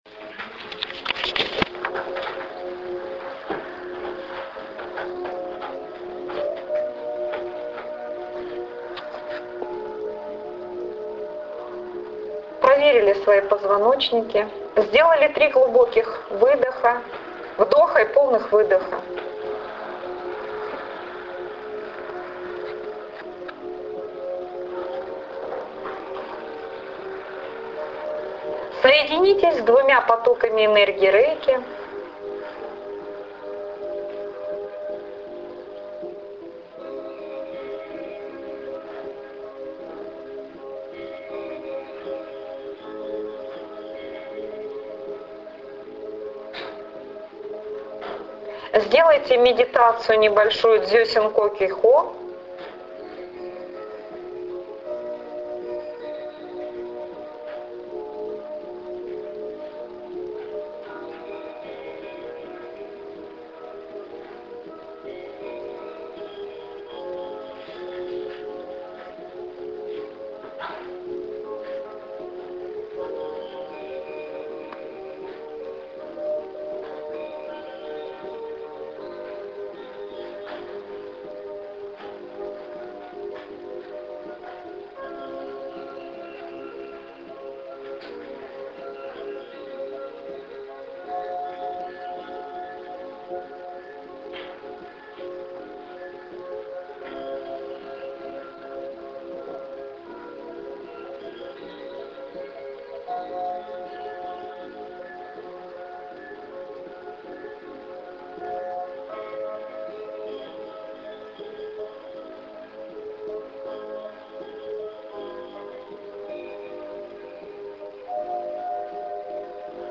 Тринадцатый пирамидальный комплекс Аудиомедитация Эта медитация предложена Крайоном в ченнеленге " Кто построит новые пирамиды?
Meditacija_Trinadcatyj_piramidalnyj_kompleks.mp3